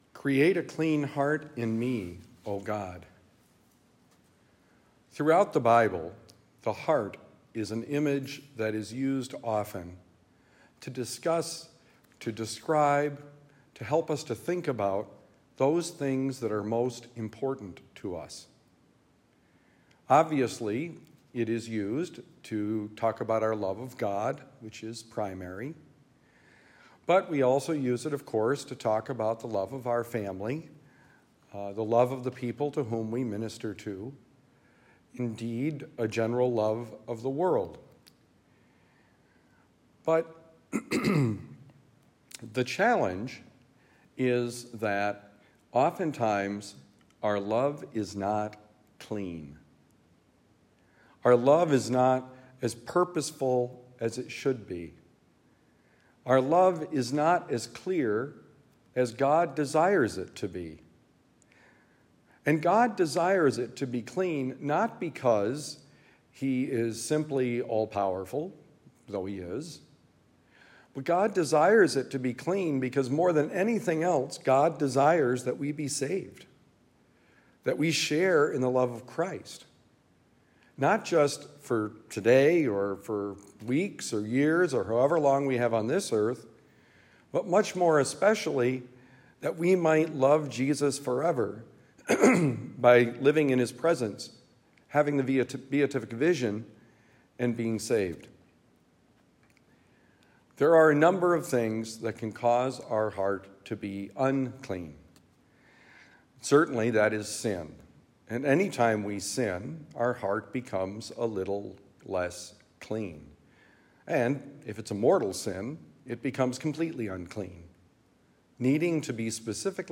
Create a clean heart: Homily for Saturday, August 17, 2024